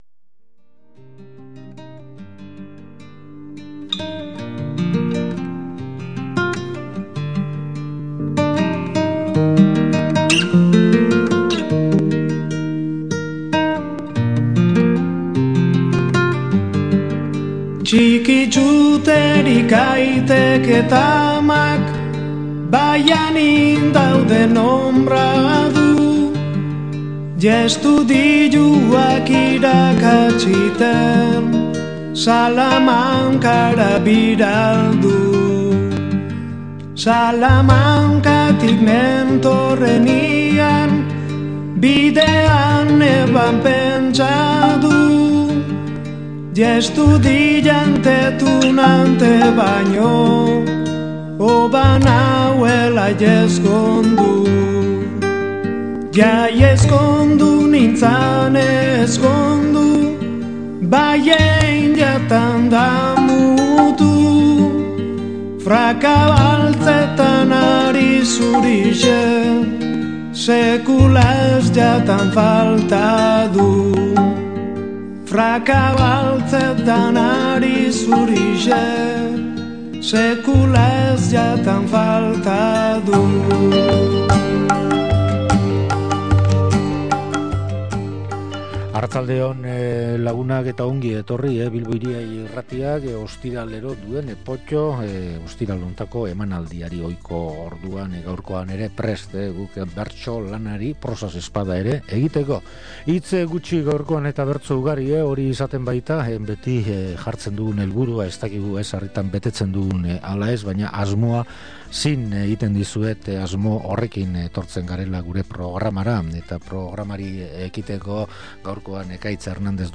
POTTO: Mungiako San Antontxu saioa